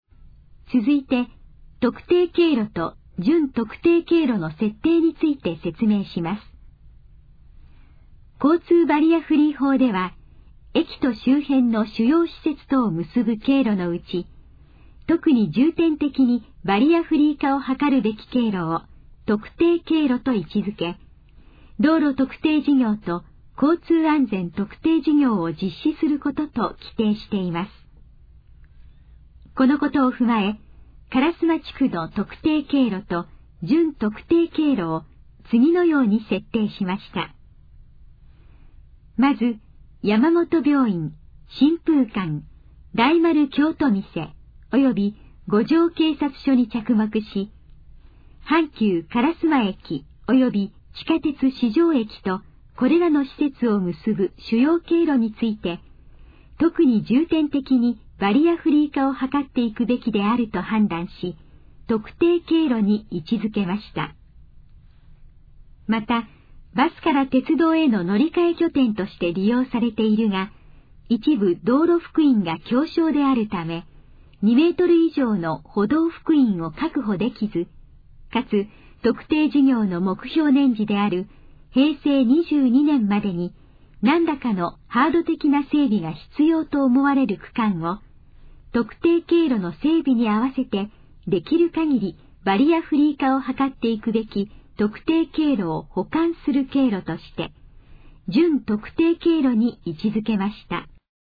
以下の項目の要約を音声で読み上げます。
ナレーション再生 約204KB